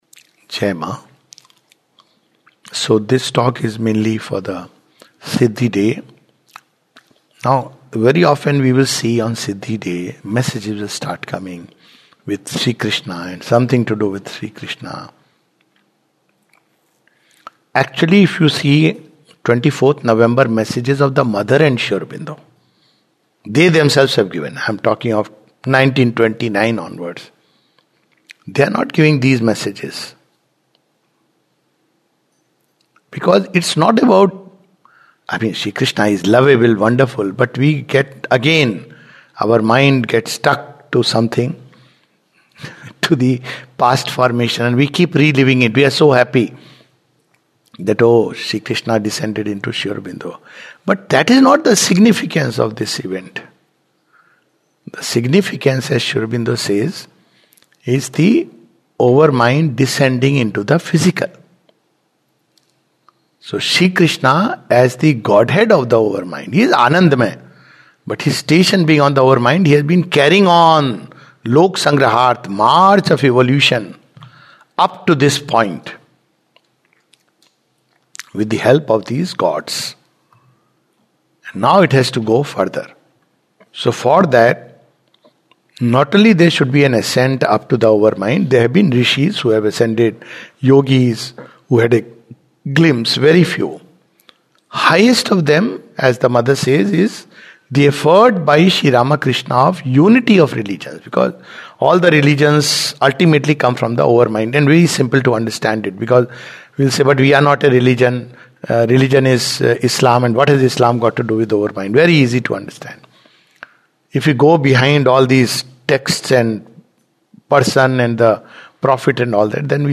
Talk TE 594